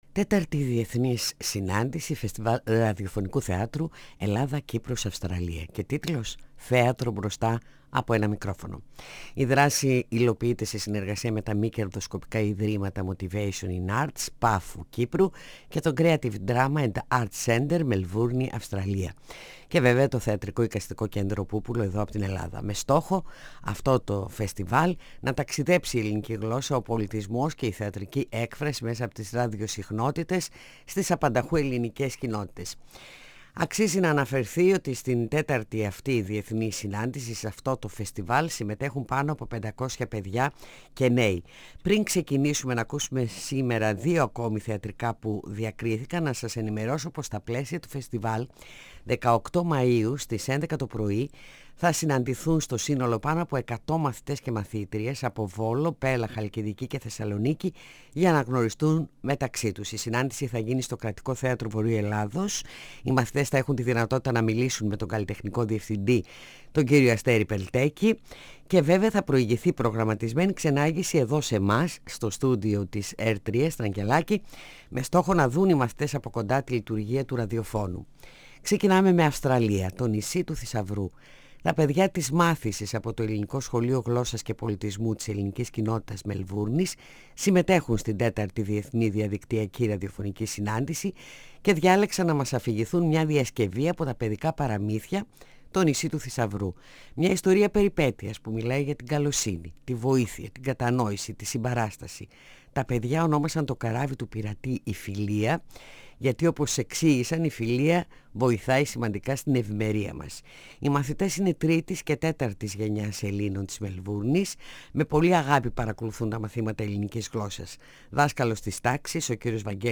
Παιδιά από την Ελληνική Κοινότητα Μελβούρνης παρουσιάζουν σε διασκευή το Παραμύθι “Το Νησί του Θησαυρού”.